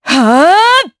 Seria-Vox_Casting3_jp.wav